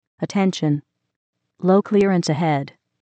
If you want more than a "ding," you can select more appropriate alerts for each POI file.